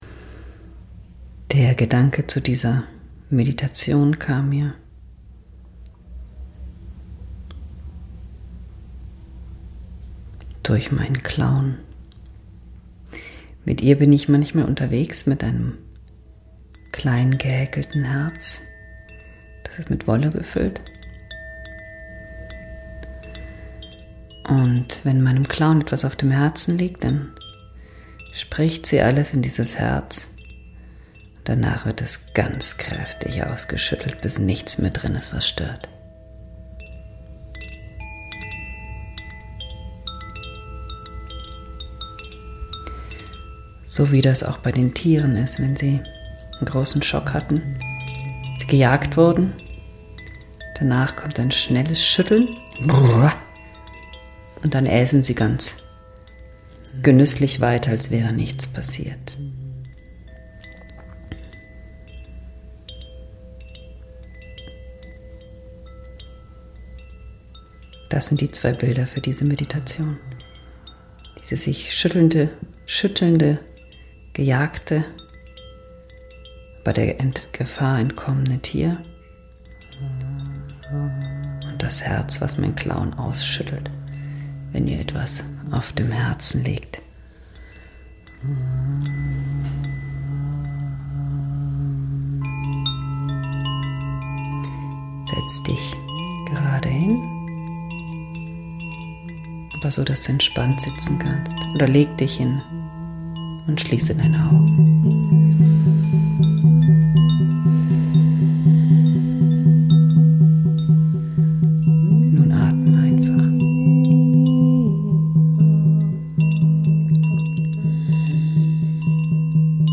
Ihr hört eine Meditation, mit der ihr Verbindung zu eurem Herzen aufnehmen und Herzschwere abschütteln könnt.